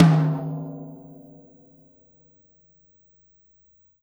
Tom Shard 07.wav